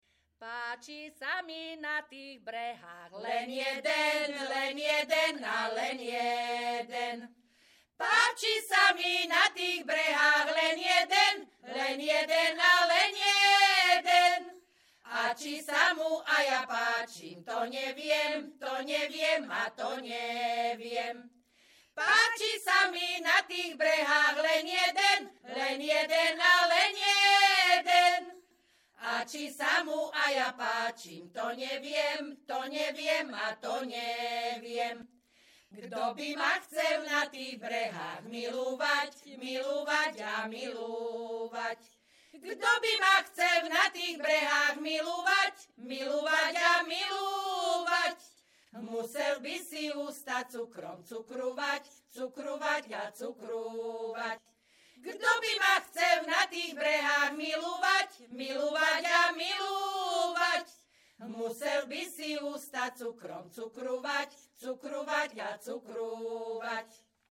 Descripton ženský skupinový spev bez hudobného sprievodu
Performers Spevácka skupina Hronka z Brehov
Place of capture Brehy
Key words ľudová pieseň